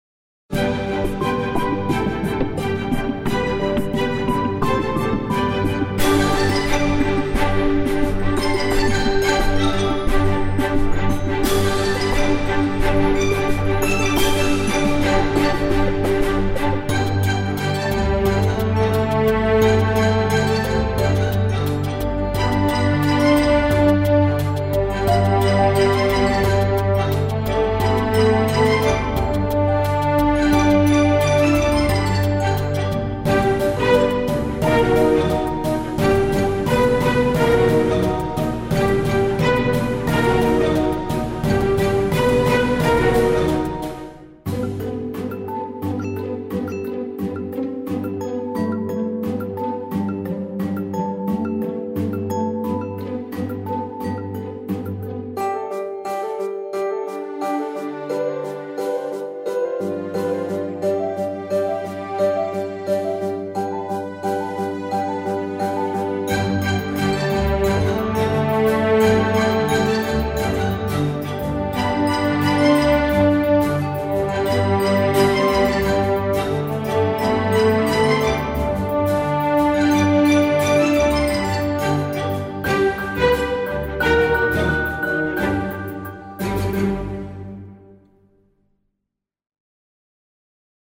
Orchestral track for adventure and puzzle.
Animation-like orchestral track for adventure and puzzle.